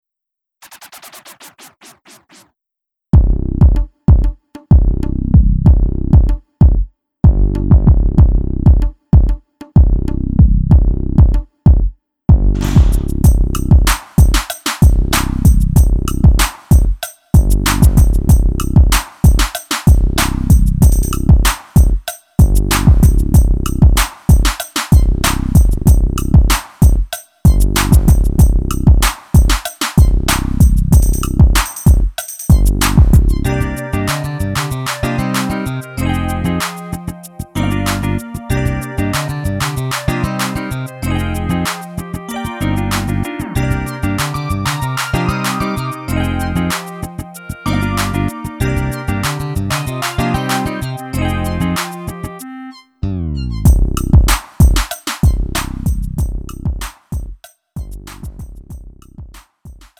음정 원키 3:15
장르 구분 Lite MR